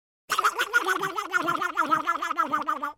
В коллекции представлены как фоновые звуки деревни, так и узнаваемые музыкальные темы программы.